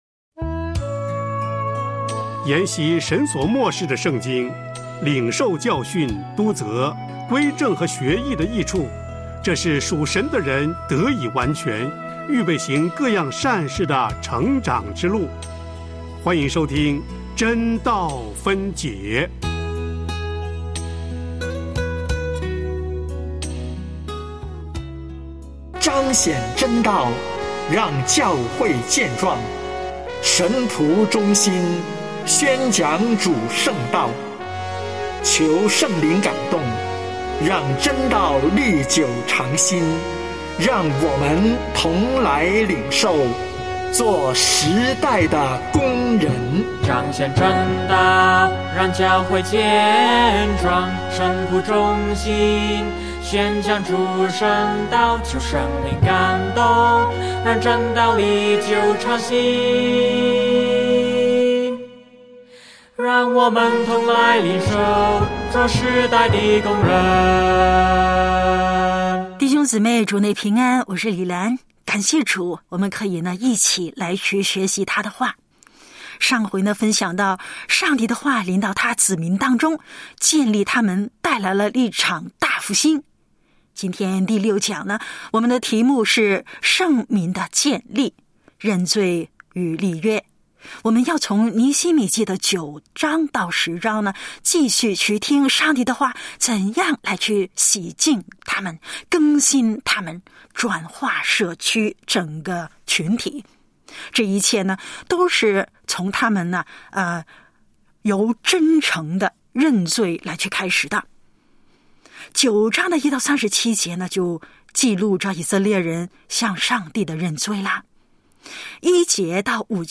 尼希米记（6）圣民的建立：认罪与立约（尼9:1-10:39）（讲员